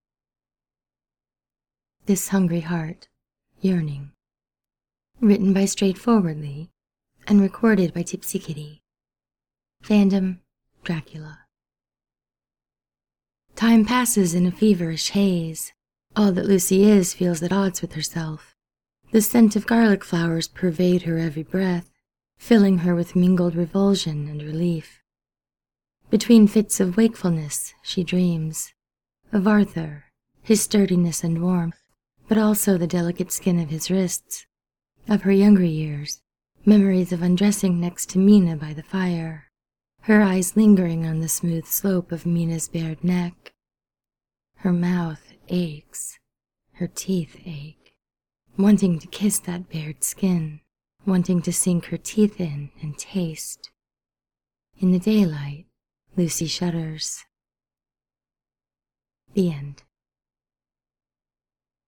without music: download mp3: here (r-click or press, and 'save link') [2 MB, 00:01:01]